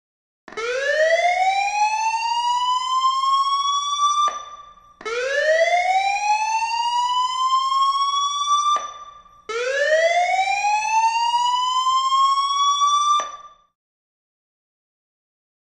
Alarm | Sneak On The Lot
House Alarm; Siren Starting From A Low Frequency Sweeping Into A Higher Frequency With Slight Reverb ( Three Times ). Medium Perspective.